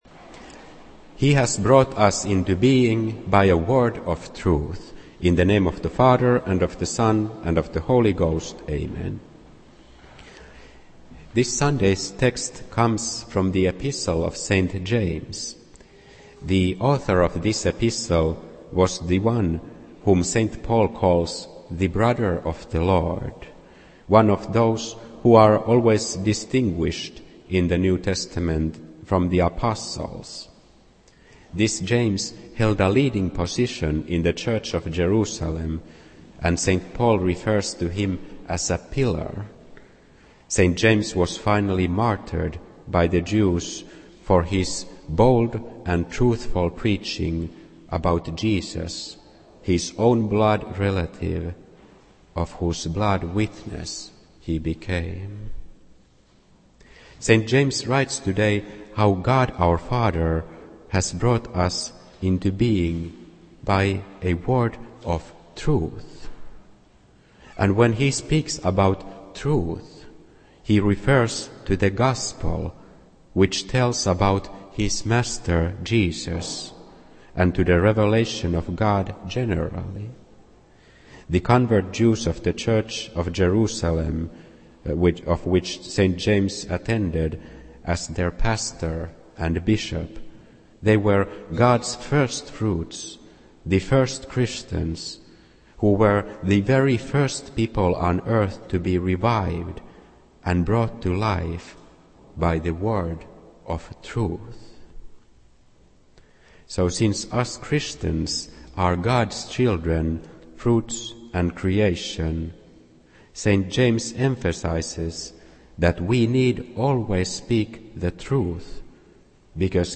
This entry was posted on Sunday, May 18th, 2014 at 12:27 pm and is filed under Sermons.